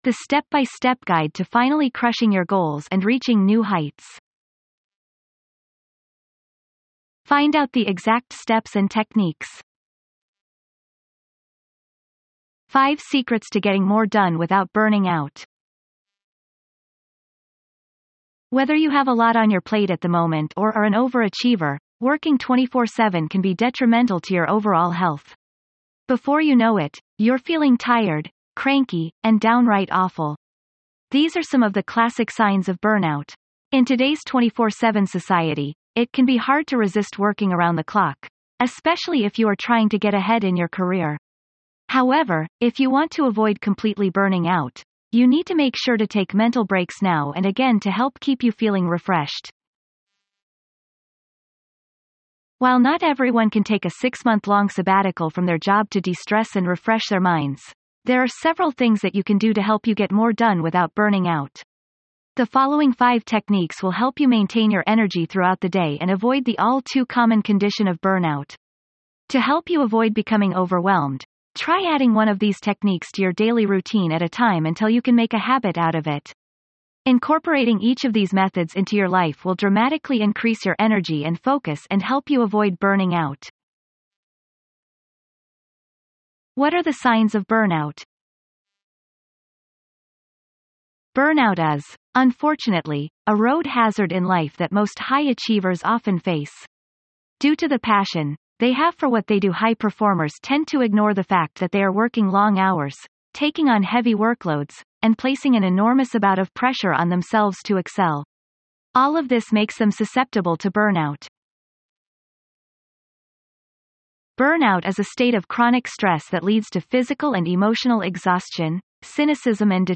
This resource reveals five practical, science-backed strategies to help you boost focus, protect your energy, and stay productive—without pushing past your limits. You’ll learn how to reset your rhythm, reduce mental fatigue, and work with more clarity, confidence, and control. Includes an AI-narrated audio session and a matching eBook to guide your next step forward.